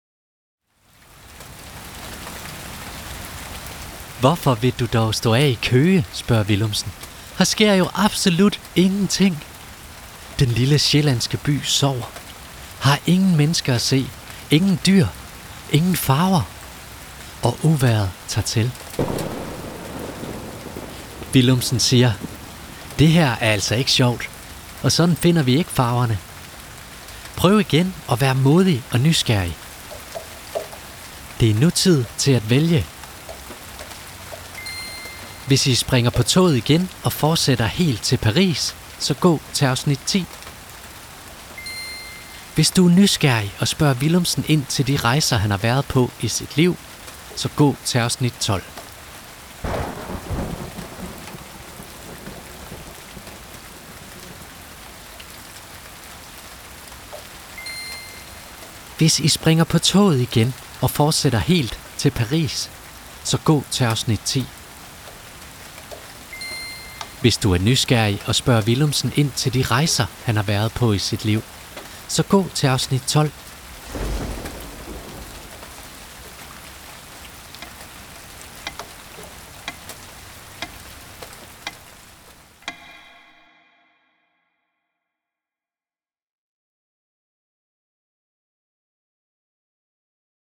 I den her lydfortælling skal du på eventyr med Willumsen.